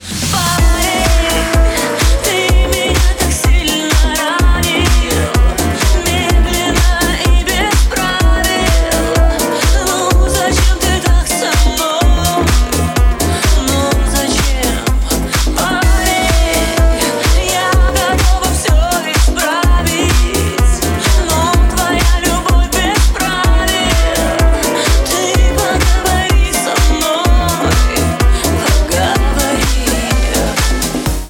• Качество: 128, Stereo
громкие
remix
deep house
dance
Club House
Саксофон